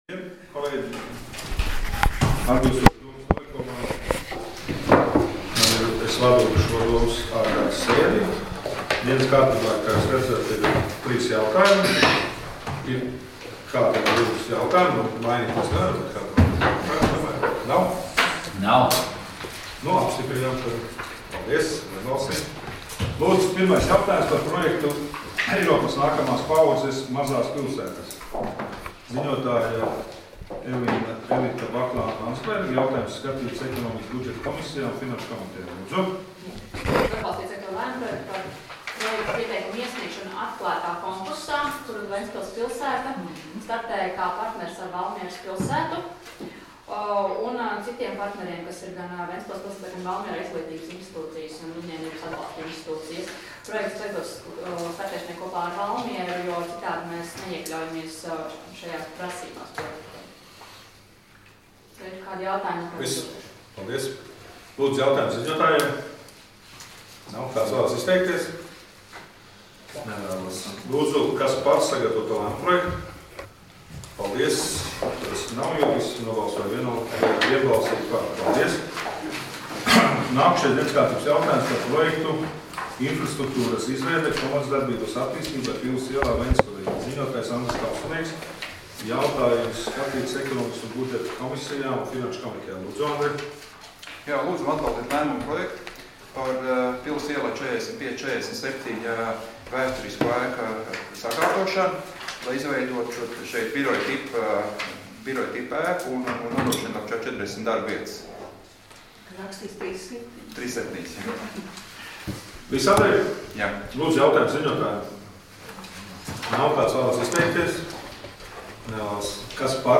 Ārkārtas Domes sēdes 27.03.2018. audioieraksts